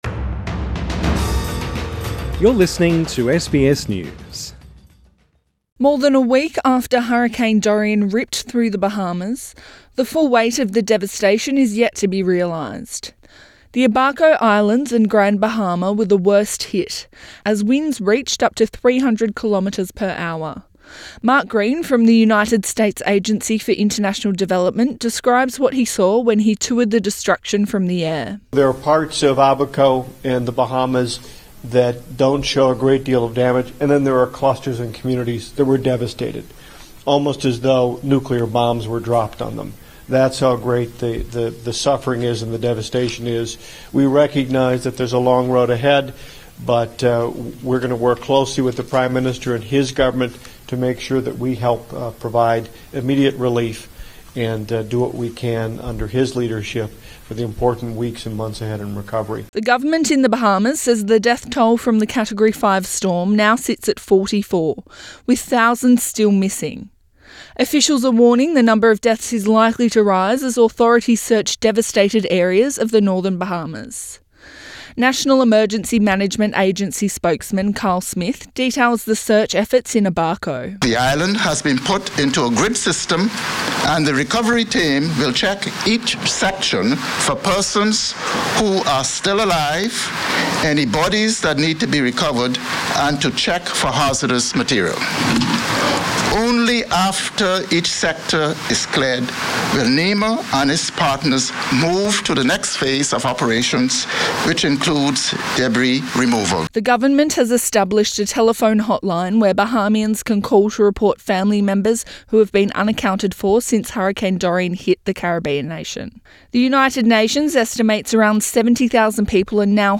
Residents in the Bahamas speak of the terrifying onslaught of Hurricane Dorian, and the massive task ahead of them.